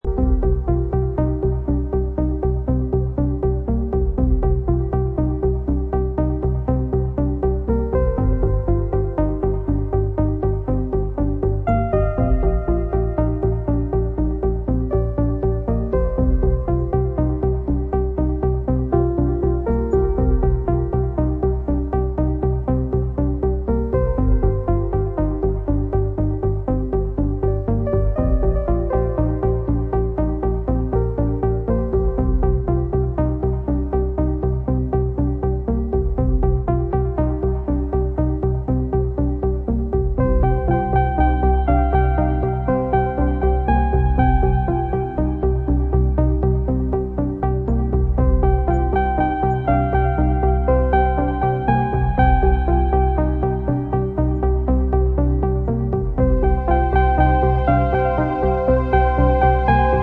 Driving, thrilling and emotionally charged
techno/house/trance
Techno